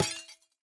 Minecraft Version Minecraft Version latest Latest Release | Latest Snapshot latest / assets / minecraft / sounds / block / copper_bulb / break3.ogg Compare With Compare With Latest Release | Latest Snapshot